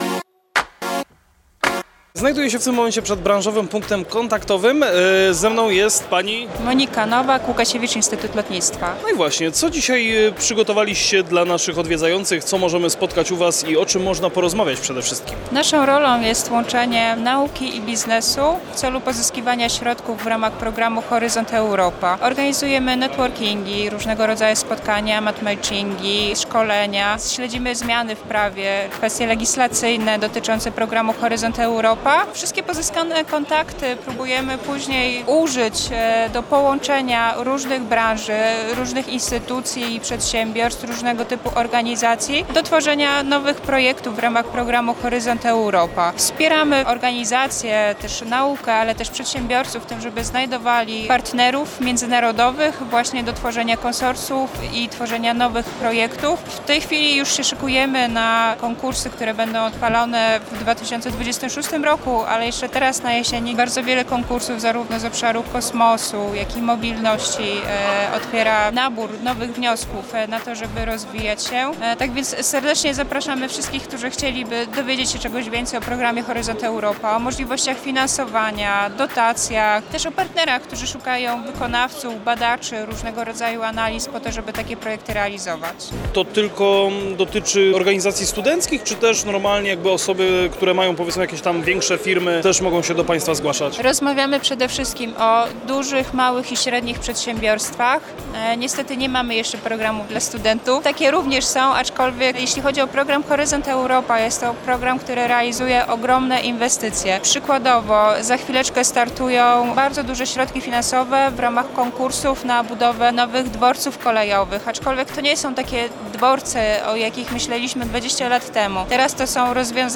Zapraszamy Was na kolejny wywiad z serii materiałów nagranych podczas 16. Międzynarodowych Targów Kolejowych TRAKO 2025!